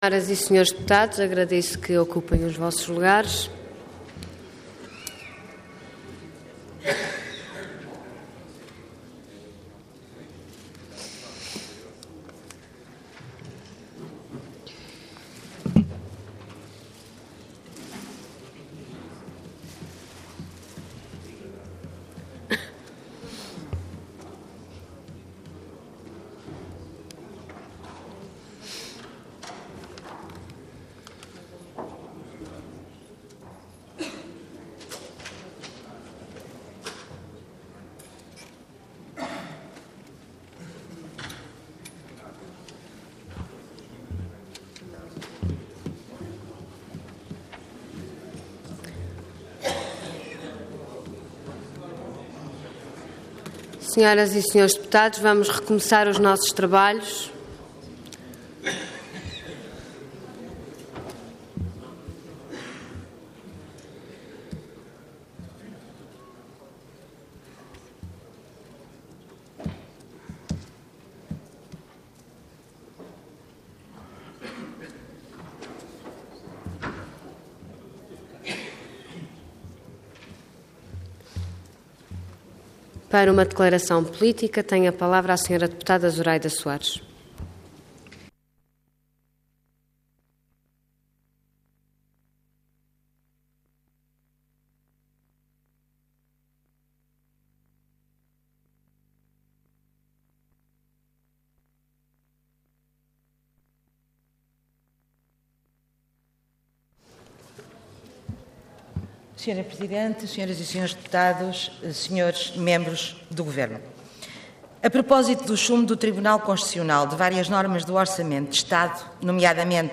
Parlamento online - Intervenção da Deputada Zuraida Soares do BE - Declaração política.